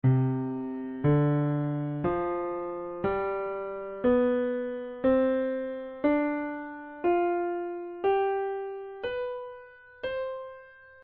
Dados: Instituto Ricardo Jorge Mínimo: 79 000 Máximo: 86 000 Instrumento: Piano Key: C Major Scale Range: 2 Octaves Track Tempo: 1x Nascimentos-em-Portugal.mp3 Quote